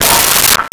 Metal Lid 02
Metal Lid 02.wav